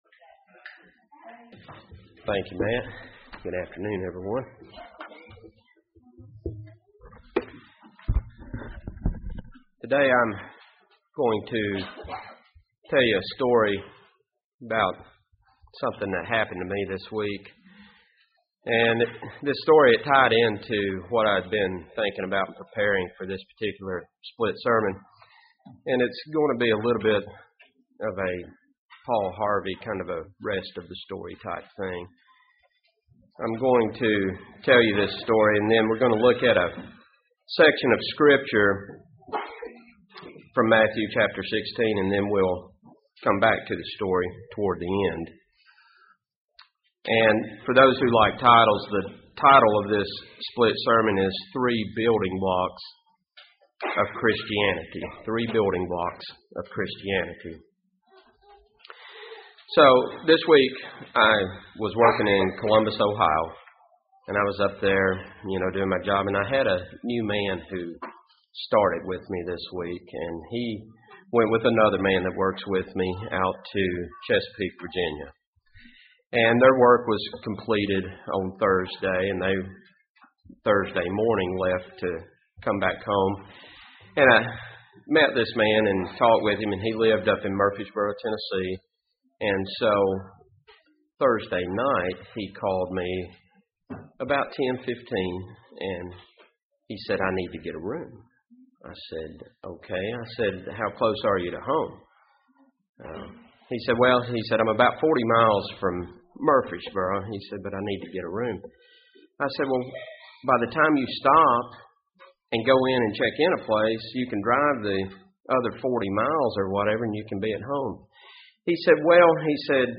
This sermon looks at three fundamental building blocks of Christianity and what it means when Christ tells us to deny our self, take up our cross and to follow Him.
Given in Huntsville, AL